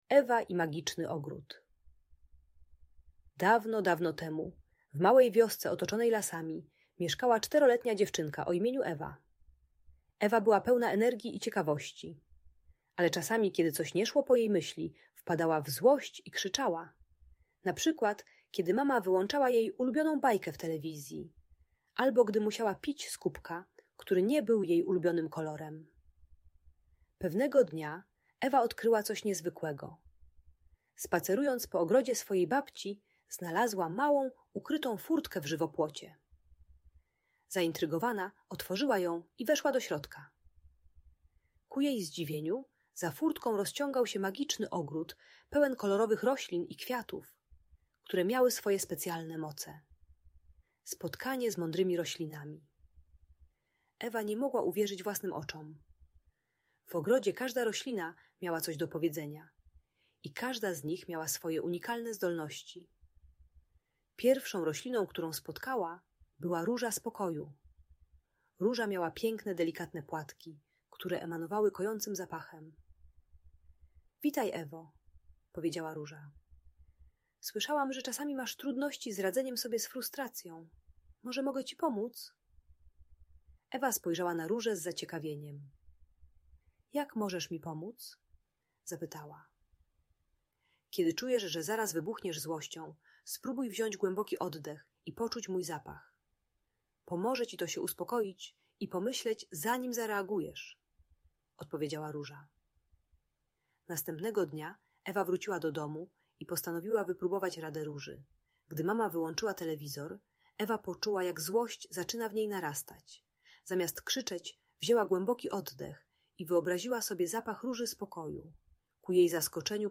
Ewa i Magiczny Ogród - Audiobajka